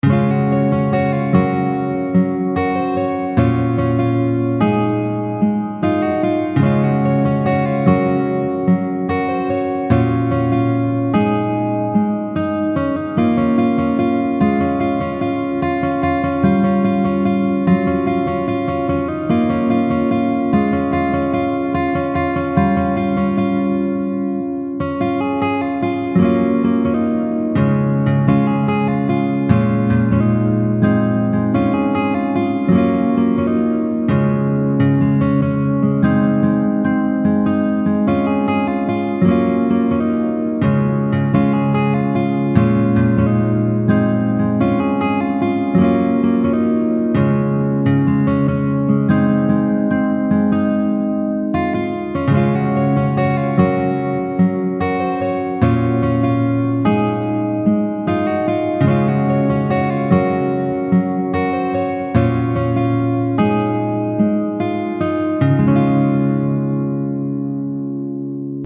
une mélodie harmonieuse
Audio de la tablature complète :
Accordage : Standard
Capodastre : 6ᵉ case